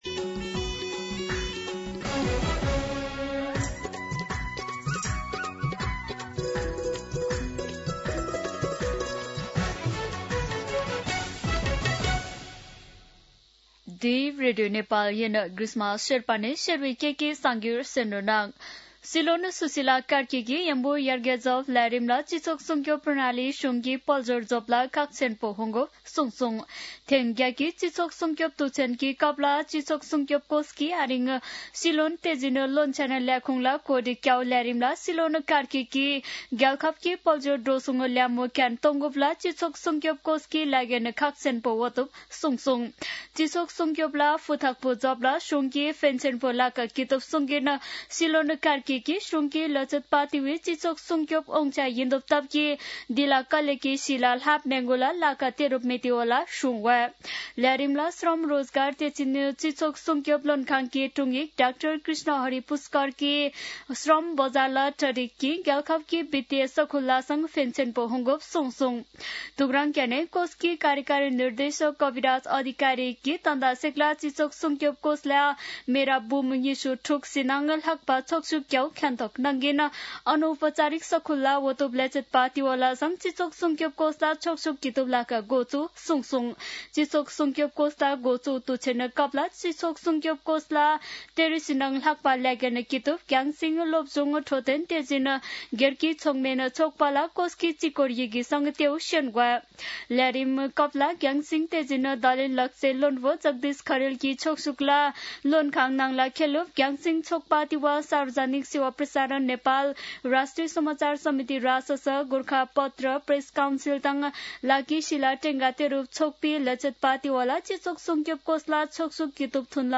शेर्पा भाषाको समाचार : ११ मंसिर , २०८२
Sherpa-News-8-11.mp3